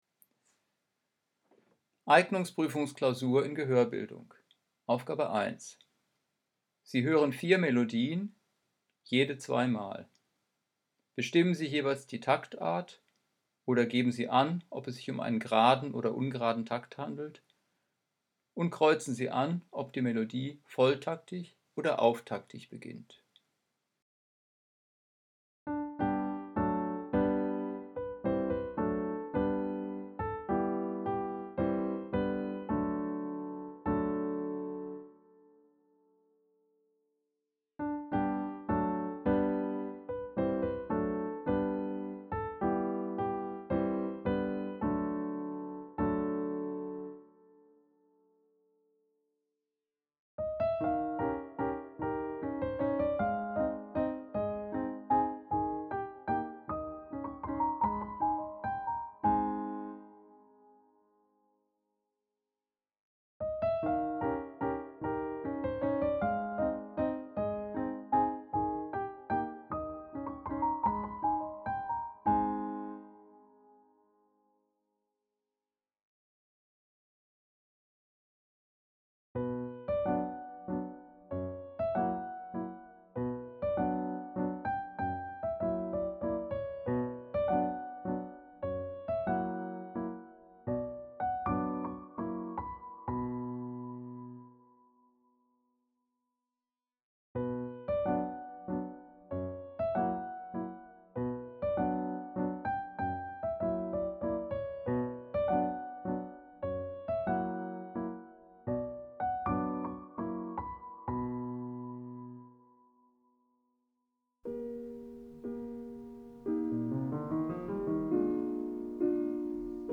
Hoerbeispiele-Eignungspruefung in Gehoerbildung .mp3